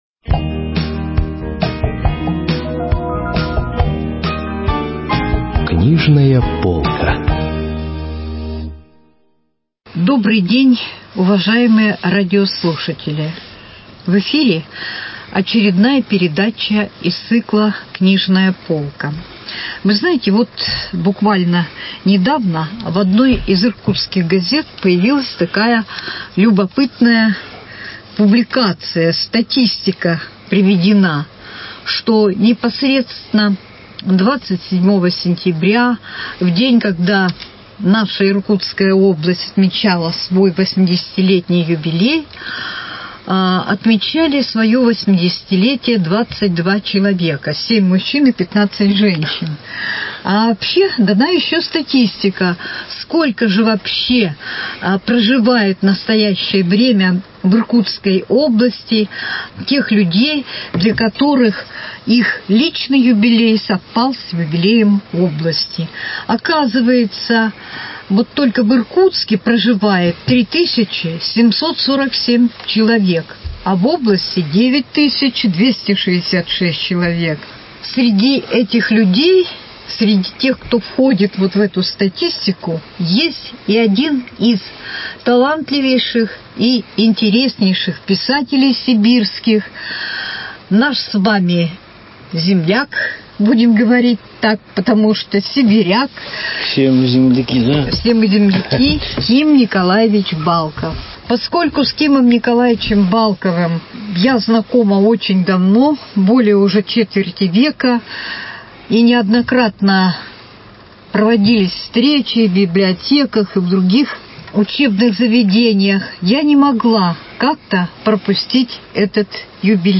Передача из коллекции подкастов газеты "Иркутск" от 04.10.2017 года.
беседует с гостями студии